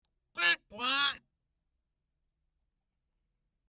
Pato Criollo (Cairina moschata domestica)